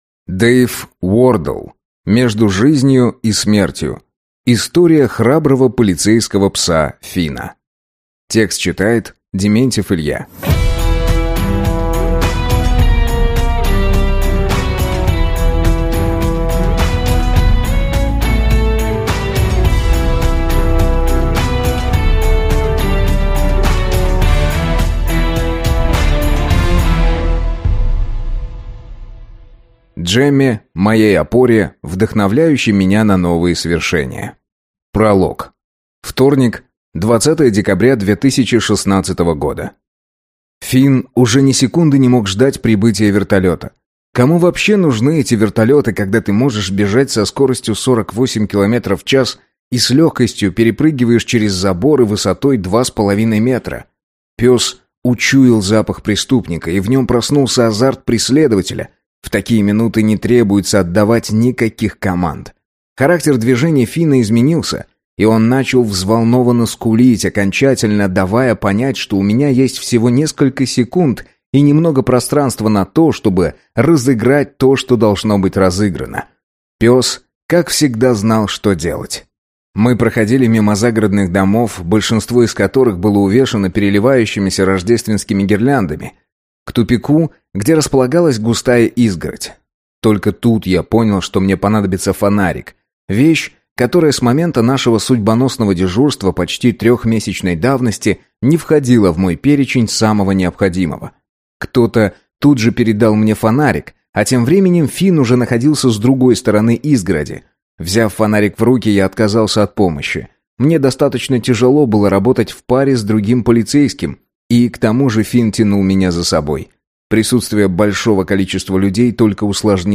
Бестселлер Amazon и Sunday TimesВы держите в руках аудиокнигу, входящую в десятку самых продаваемых произведений в Великобритании.